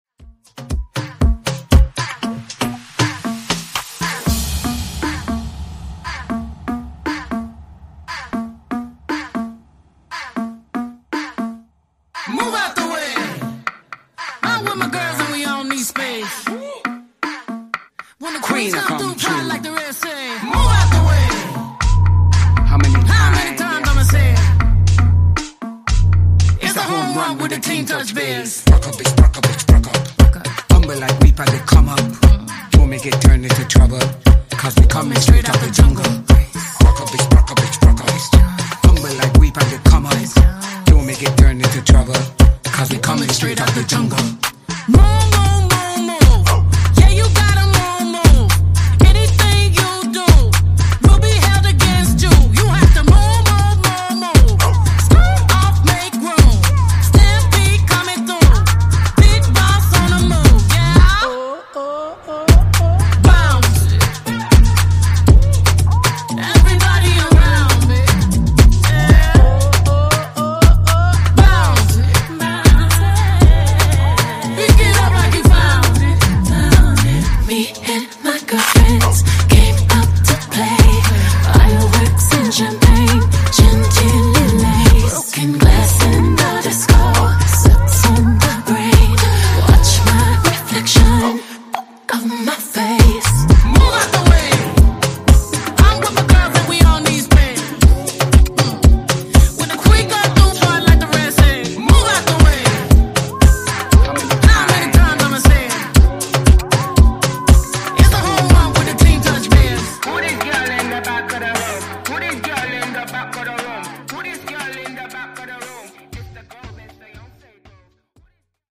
Genres: RE-DRUM , REGGAETON
Dirty BPM: 118 Time